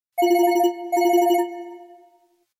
Категория : Стандартные